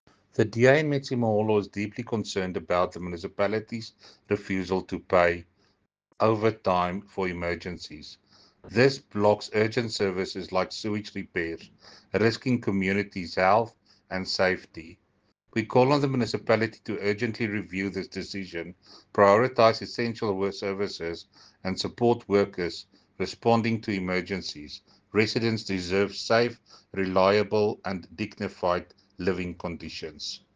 Afrikaans soundbites by Cllr Jacques Barnard and